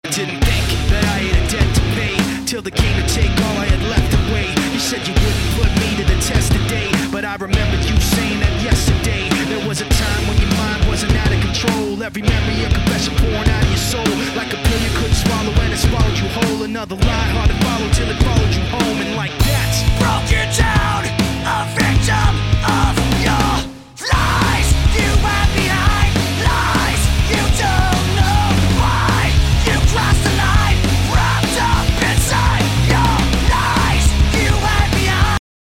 Драйвовые
nu metal
быстрые
гроулинг
американская альтернативная рок-группа.